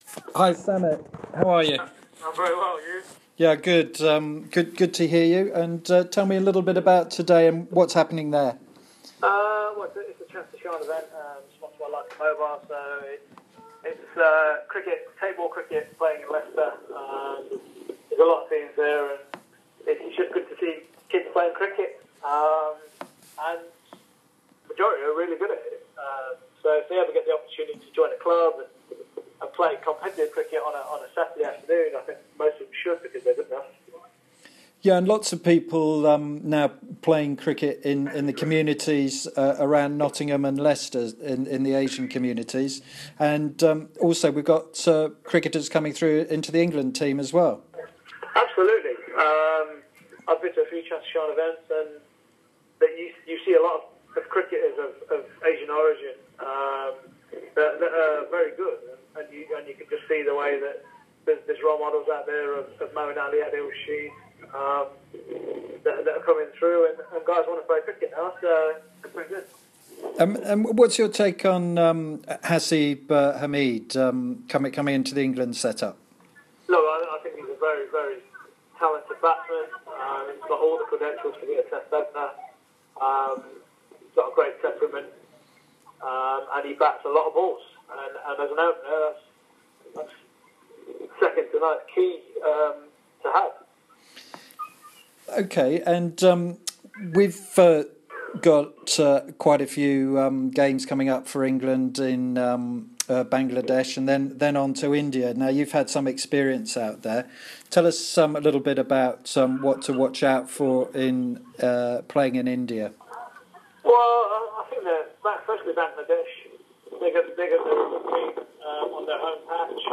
Nottinghamshire all-rounder Samit Patel speaks to Cricket World on England's tours of Bangladesh and India and many more!